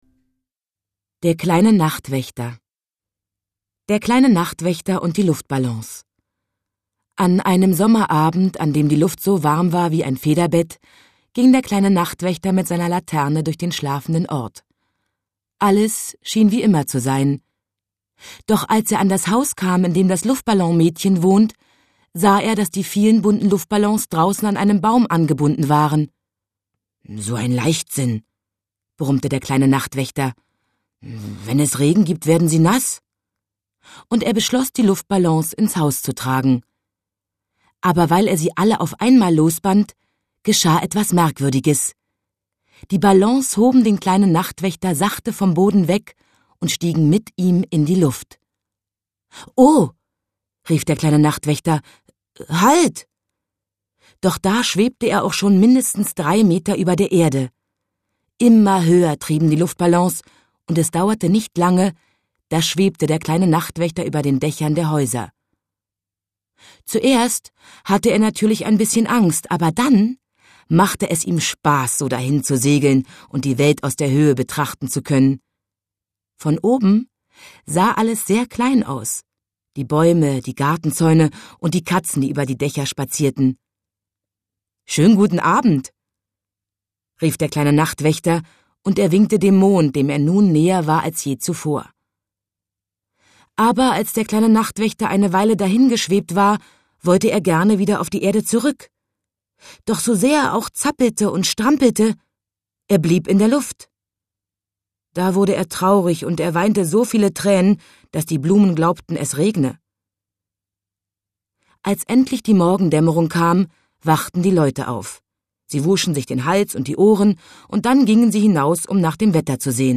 Lesung mit Christiane Paul (2 CDs)
Die Lesung mit Christiane Paul ist ganz zeitgemäß und dabei so zeitlos wohltuend wie die seit Jahrzehnten geliebte Buchvorlage.